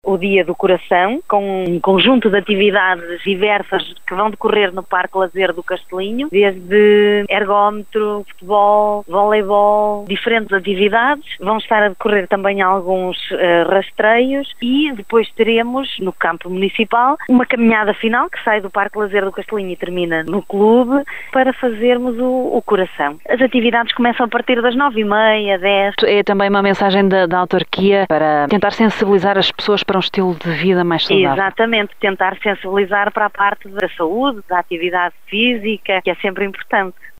Vila Nova de Cerveira reservou o dia de hoje para a actividade física. Para assinalar o Dia Mundial do Coração, a autarquia local está a realizar várias actividades no campo do Castelinho e no Campo de Futebol Municipal, como revela a vice-presidente, Aurora Viães: